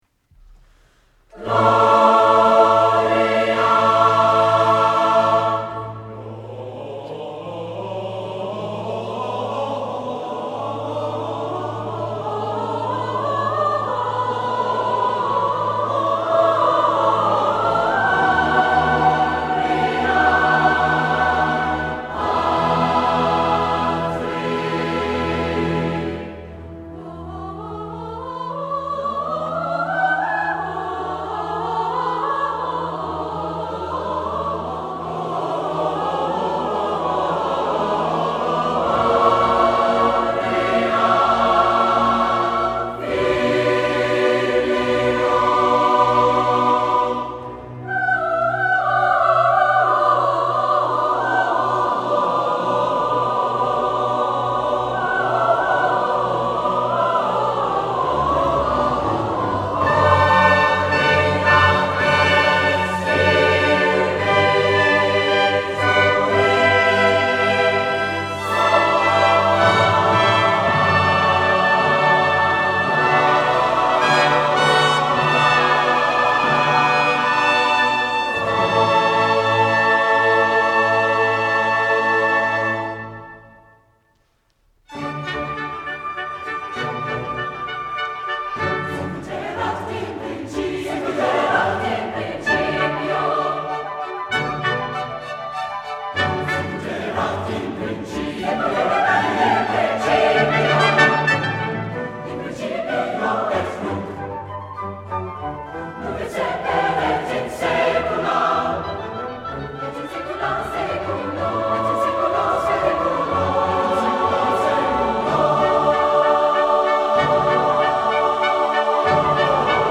SINGERS
November 6 – University Centre Auditorium, Bach Magnificat with Victoria Chamber OrchestraDec 13 – Christ Church Cathedral Memorial Hall, Christmas with Friends Concert